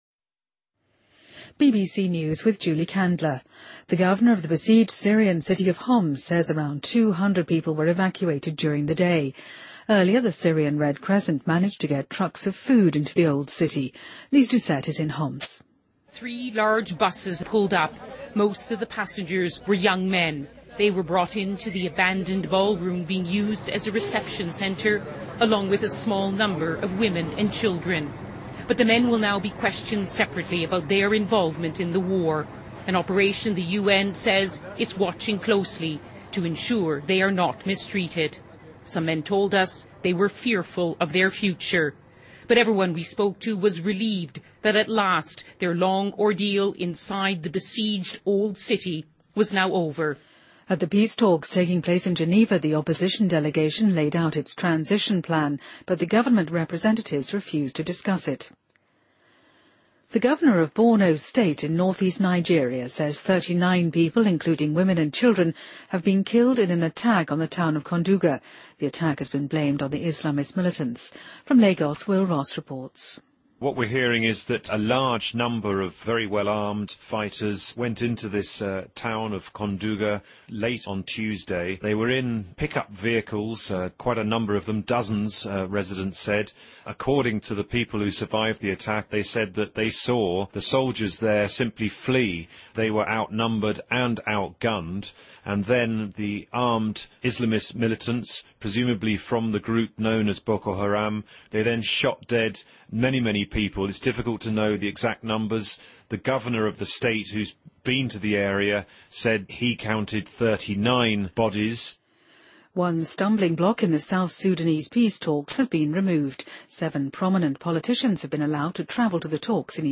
BBC news,2014-02-13